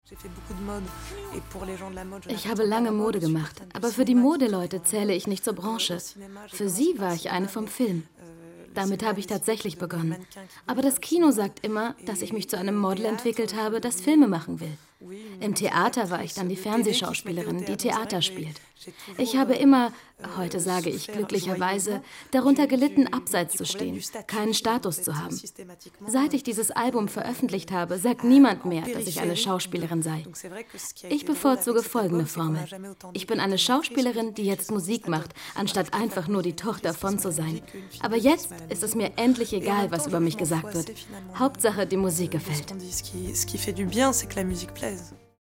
Warme,sinnliche,frische,femine aber auch markante Stimme, deutsch für Funk und TV Werbung, Synchron, Hörbücher, CD-Rom, Hörspiele, Voice Over, Imagefilme, Doku, Moderation etc
Kein Dialekt
Sprechprobe: eLearning (Muttersprache):